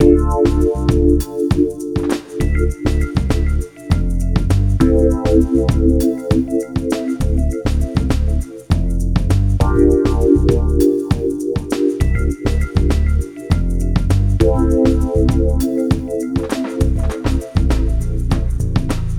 hiphop loop.wav